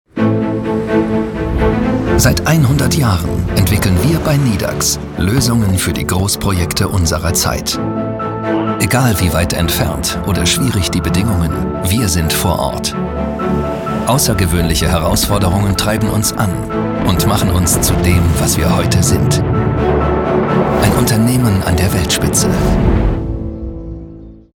•       IMAGEFILM